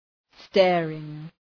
Προφορά
{‘steərıŋ}